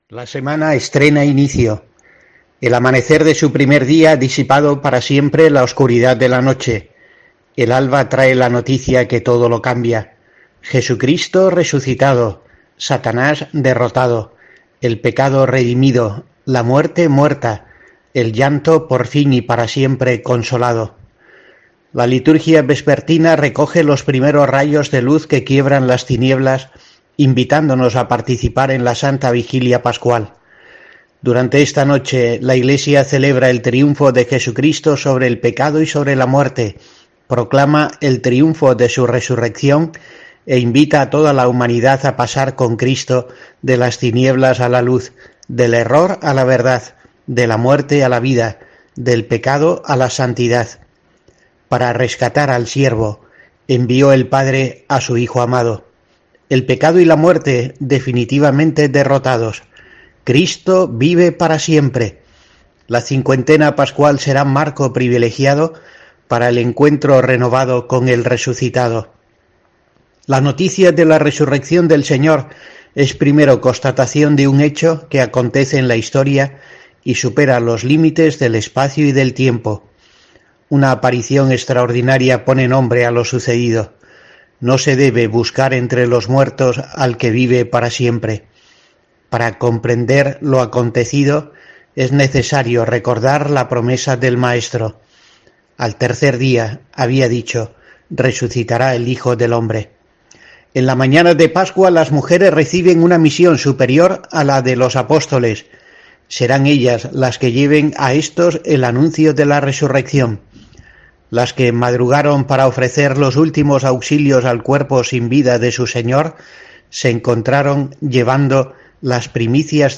"La semana estrena inicio", dice el obispo en su reflexión semanal para la programación socio religiosa de COPE.